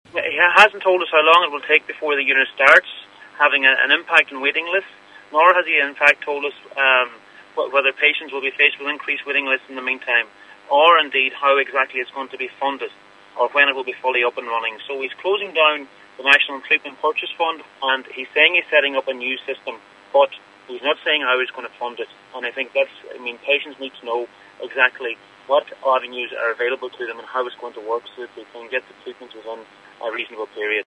Deputy McConalogue said patients need to know what avenues are available to them: